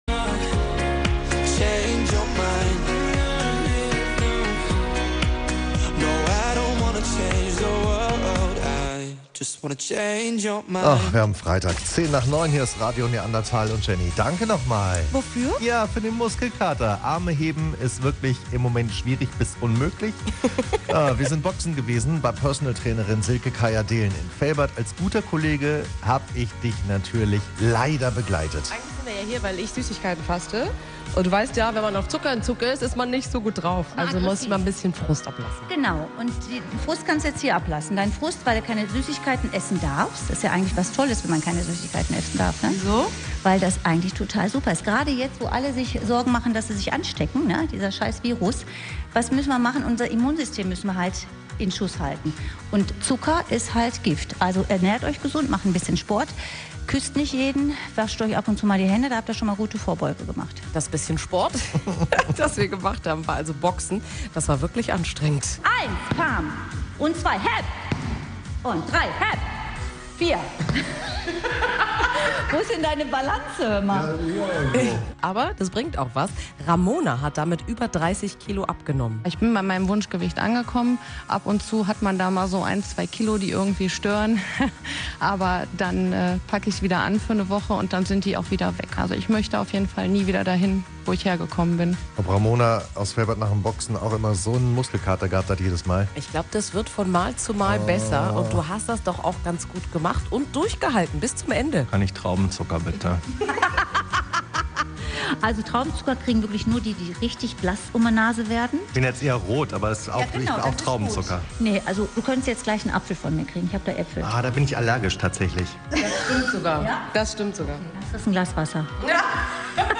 Wir waren mit dem Mikro dabei und haben die beiden belauscht: